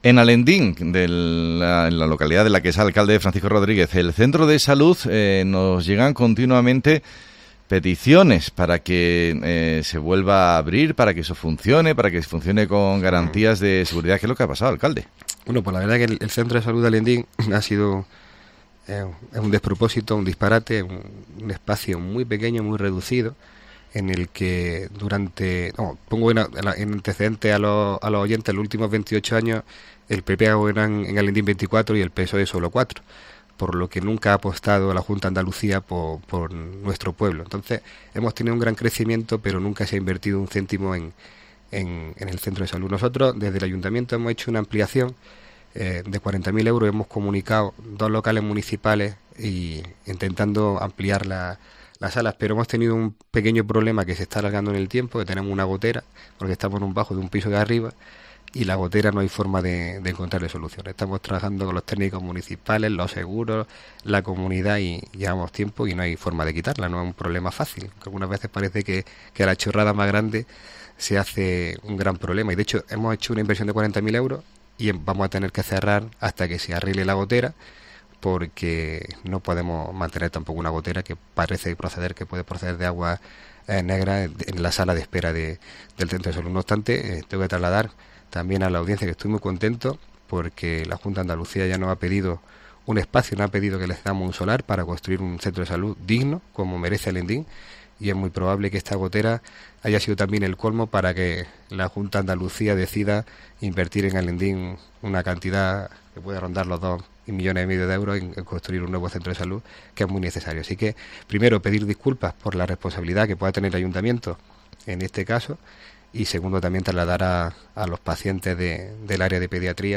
El alcalde de Alhendín nos explica la situación del centro de salud de esa localidad